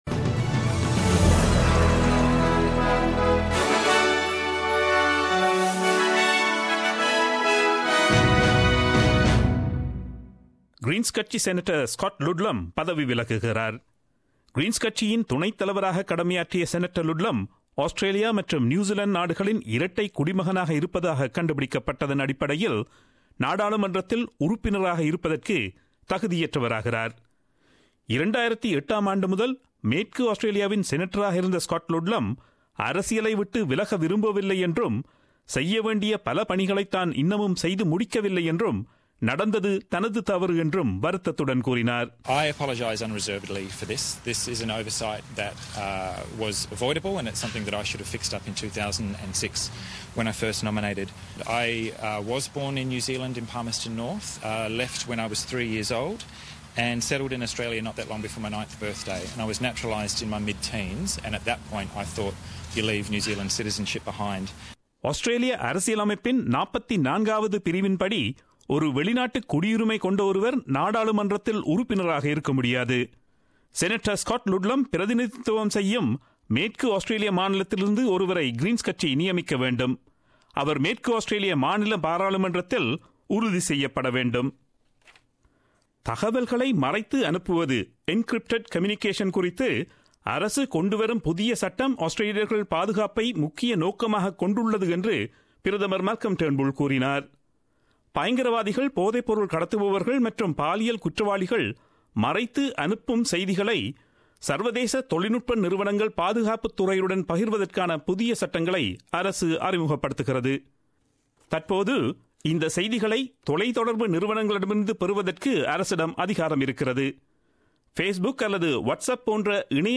Australian news bulletin aired on Friday 14 July 2017 at 8pm.